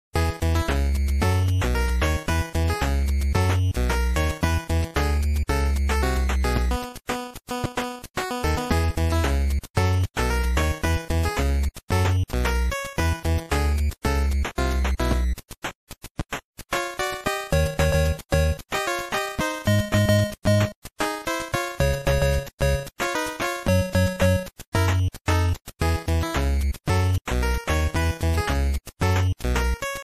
Copyrighted music sample